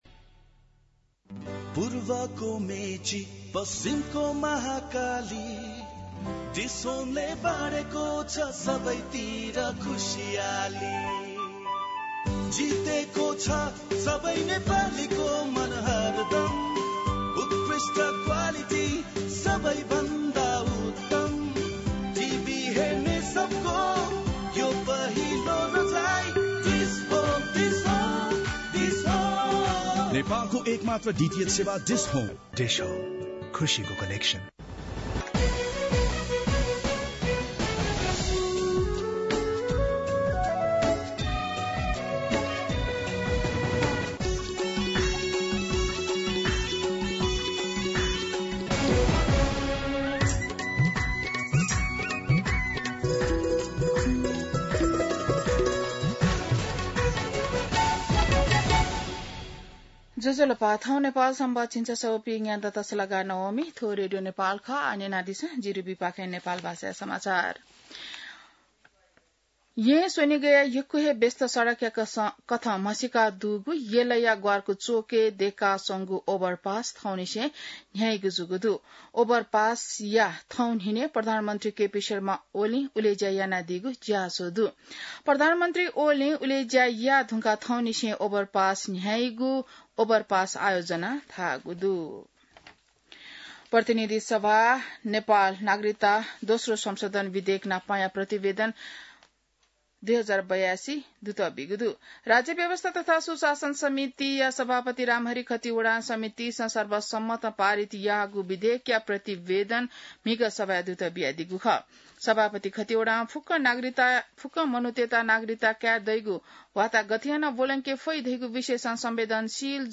An online outlet of Nepal's national radio broadcaster
नेपाल भाषामा समाचार : ६ असार , २०८२